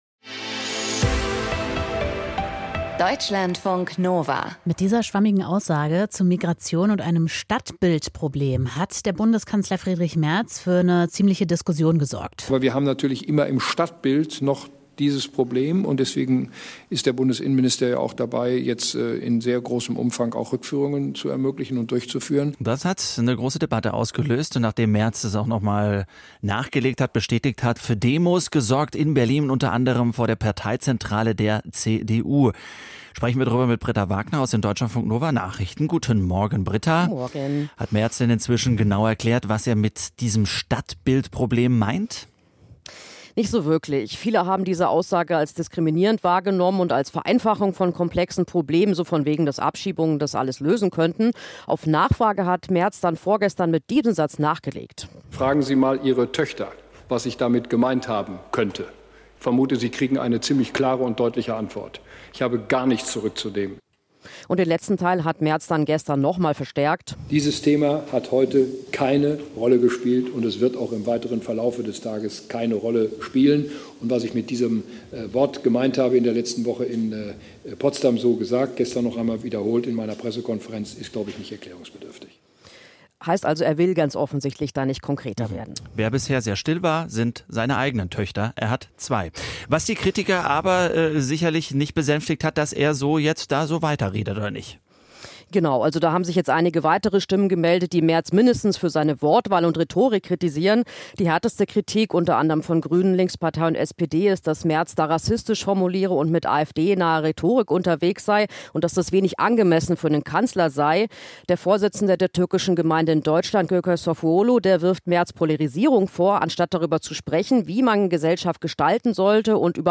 Kanzler Merz verknüpft Migration mit Kriminalität, schürt Angst vor Migranten und polarisiert die Debatte weiter. Ein Kommentar.